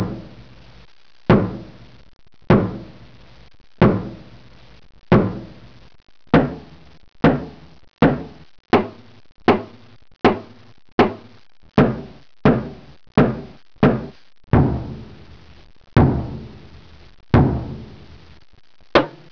Hammer
Hammer.wav